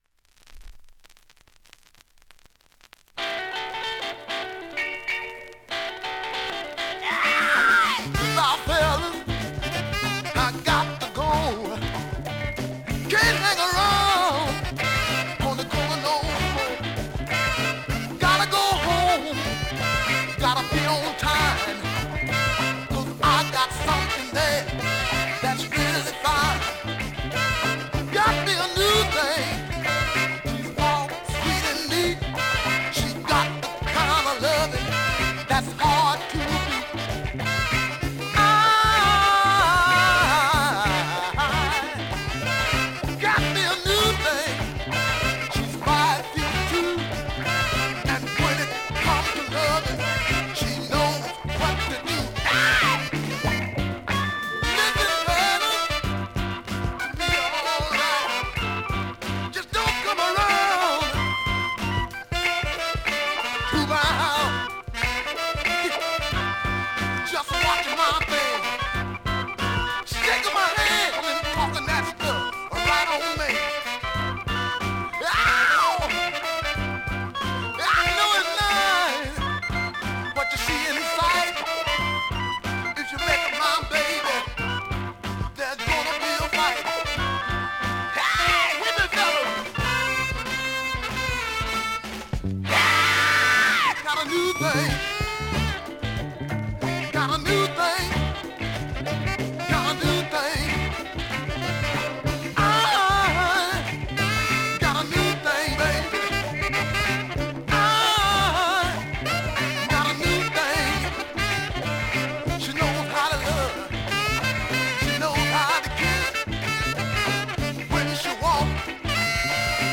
◆盤質両面/ほぼEX+